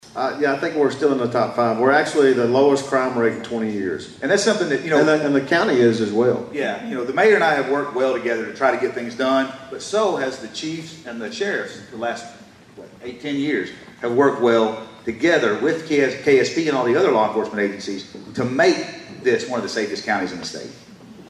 At the State of the Cities and County Address last week